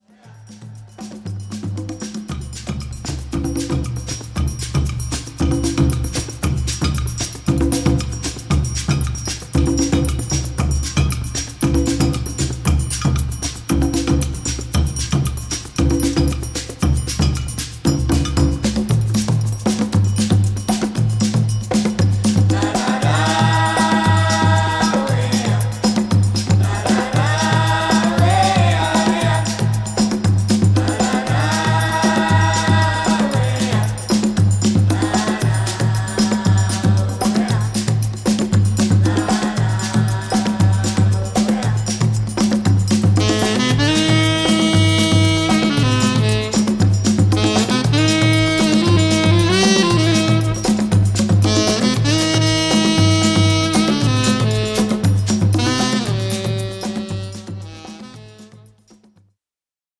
ホーム ｜ JAZZ / JAZZ FUNK / FUSION > JAZZ